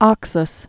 (ŏksəs)